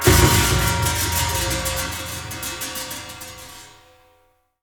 A#3 RATTL0OL.wav